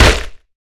peachHit2.wav